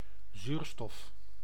Ääntäminen
Tuntematon aksentti: IPA : /ˈɒksɪd͡ʒən̩/